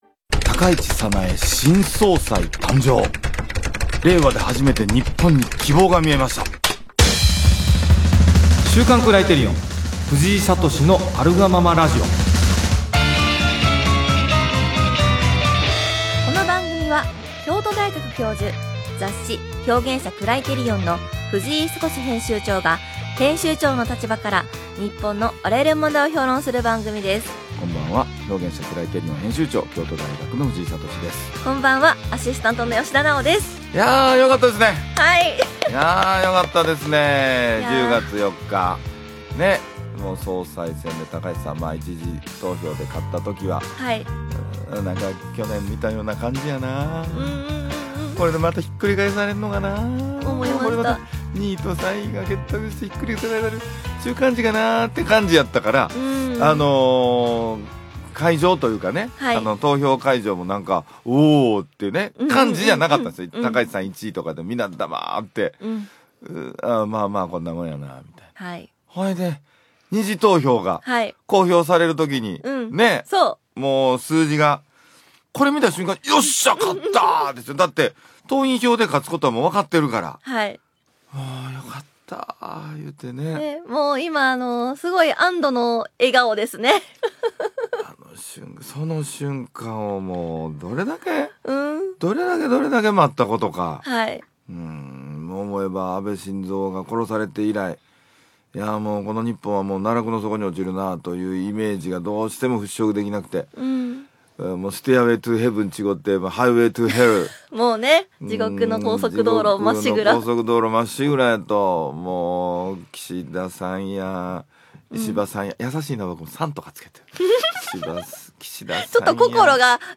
【ラジオ】高市早苗新総裁誕生！